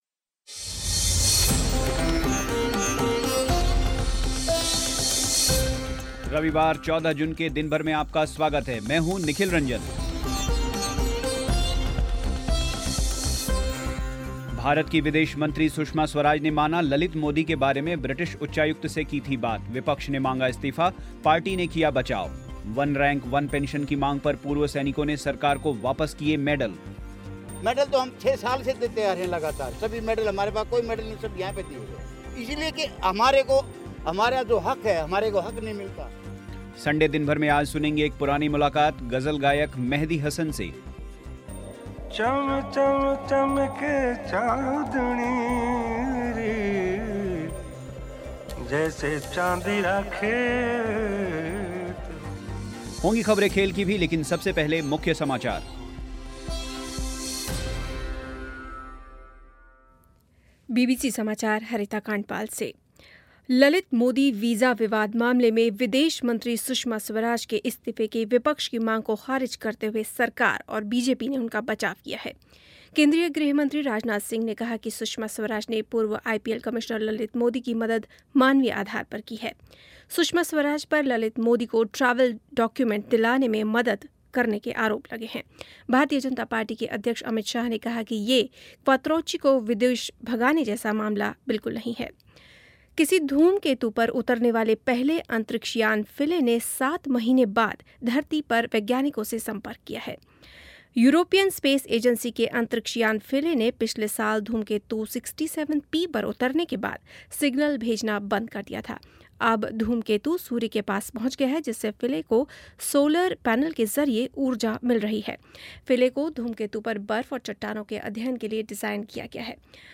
संडे दिन भर में आज सुनेंगे एक पुरानी मुलाक़ात गज़ल गायक मेहदी हसन से